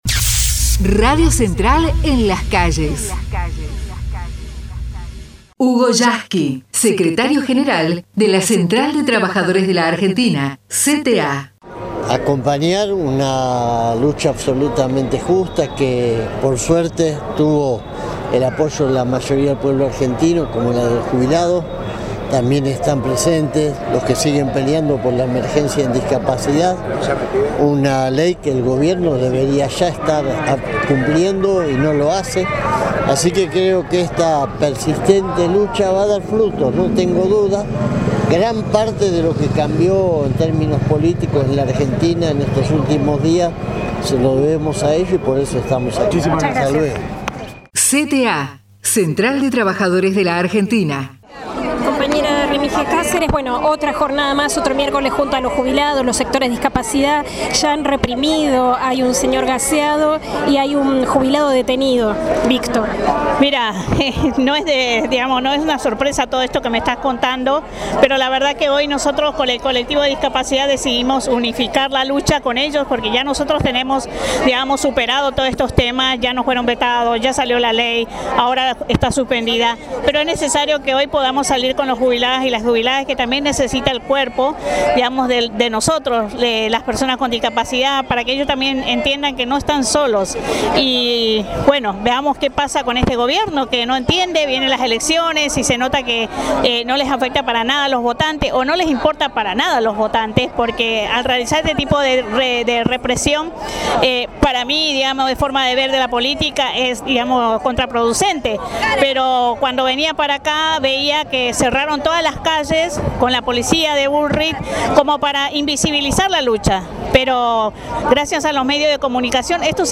MARCHA AL CONGRESO EN DEFENSA DE LOS JUBILADOS: TESTIMONIOS
marcha_al_congreso_jubilados_discapacidad.mp3